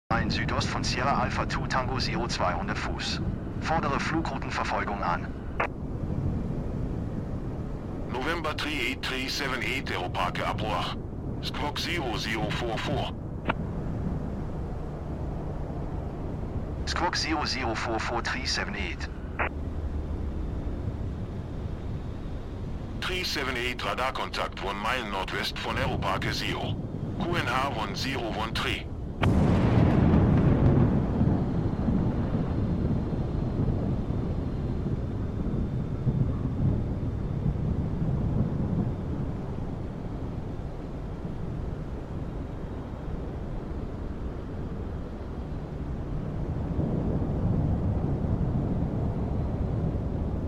bad weather in all cities sound effects free download